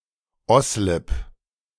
Oslip (German pronunciation: [ˈɔslɪp]